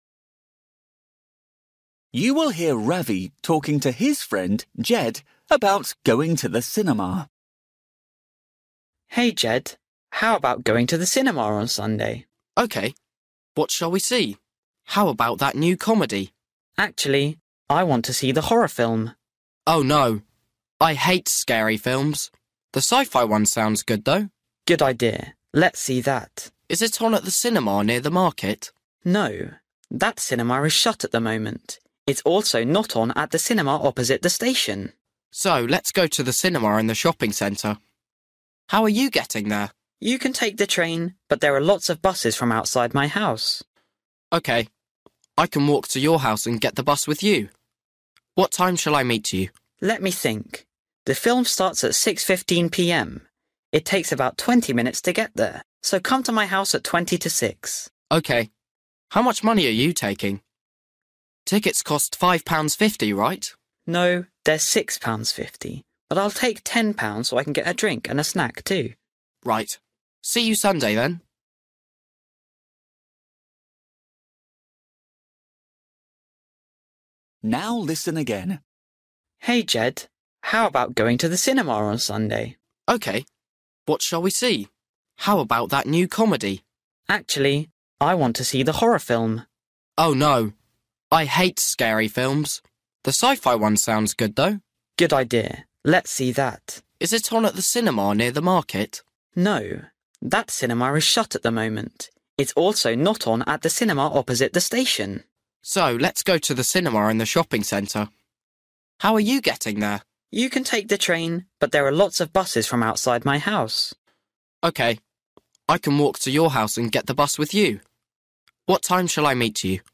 Bài tập trắc nghiệm luyện nghe tiếng Anh trình độ sơ trung cấp – Nghe một cuộc trò chuyện dài phần 37